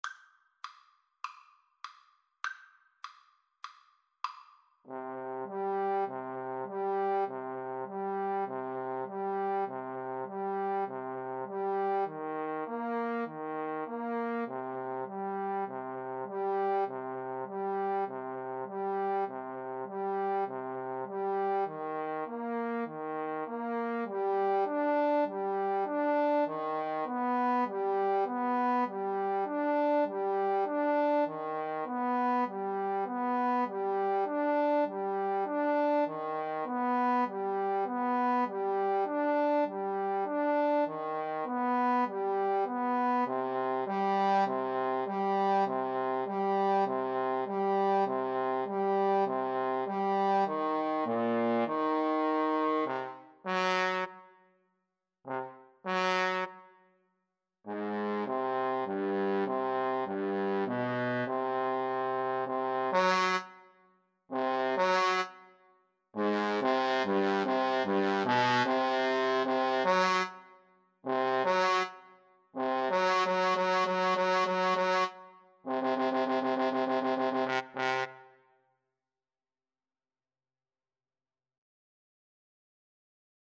4/4 (View more 4/4 Music)
C minor (Sounding Pitch) D minor (Trumpet in Bb) (View more C minor Music for Trumpet-Trombone Duet )
Trumpet-Trombone Duet  (View more Intermediate Trumpet-Trombone Duet Music)
Classical (View more Classical Trumpet-Trombone Duet Music)